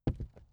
ES_Walk Wood Creaks 2.wav